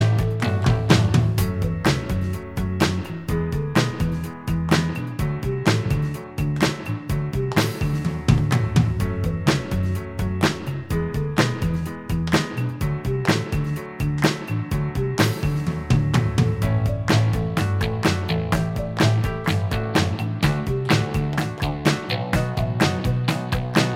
Minus Guitars Rock 3:39 Buy £1.50